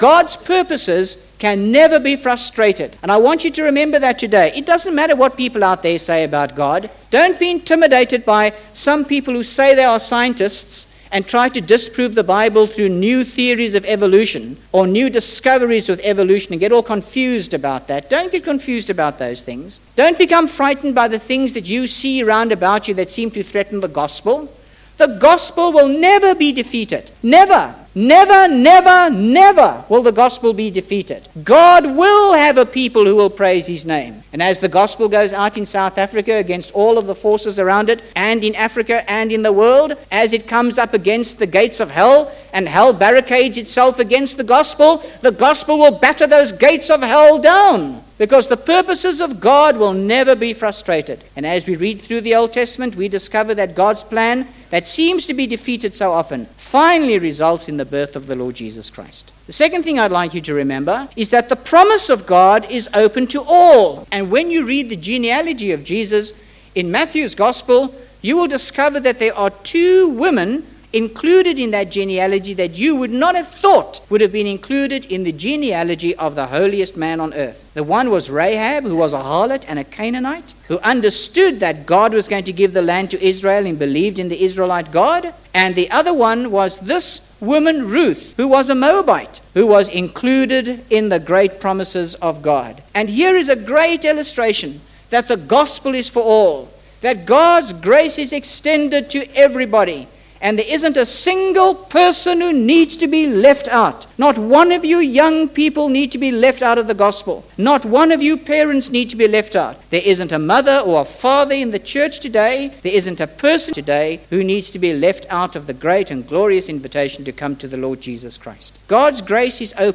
5 minute talk
5 minute talk The Gospel is for all .mp3